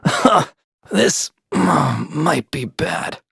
Kibera-Vox_Dead.wav